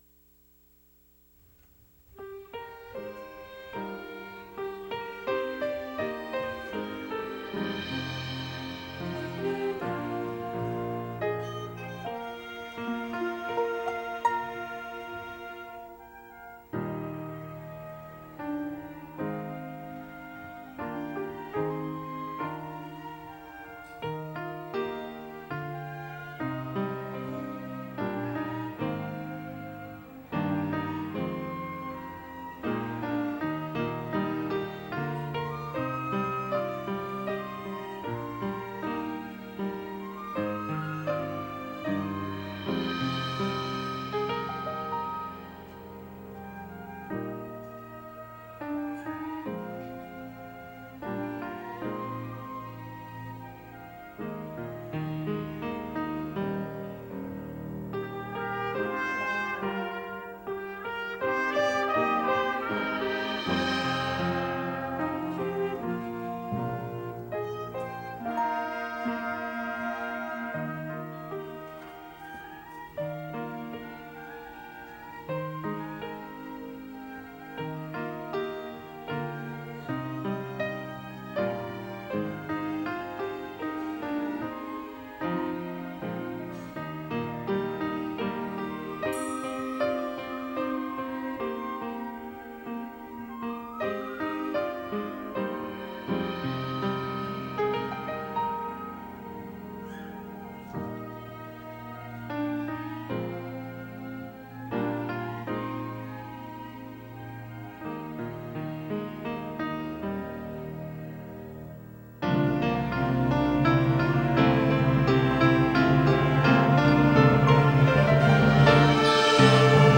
Come Thou Fount by the Faith Baptist Orchestra.
Thoroughly enjoyed the hummingbird photographs and the worship music (“Come thou fount of every blessing”, etc.).
come-thou-fount-orchestra.mp3